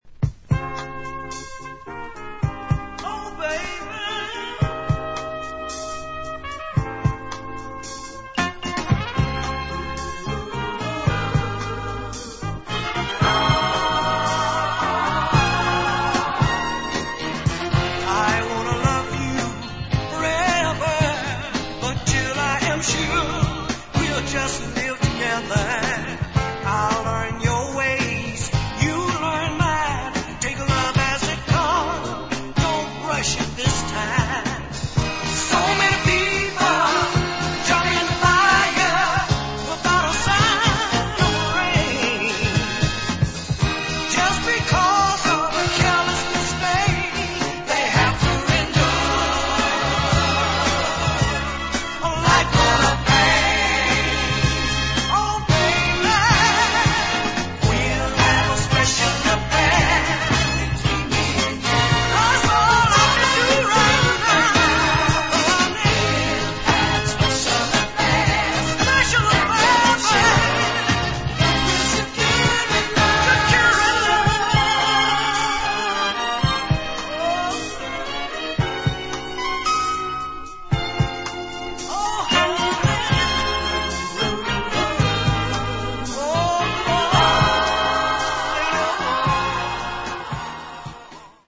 This is a great little 70's mover with the great vocals